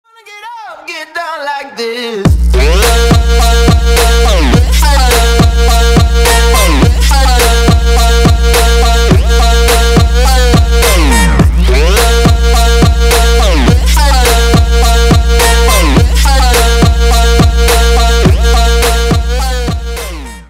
громкие
Electronic
Trap
качающие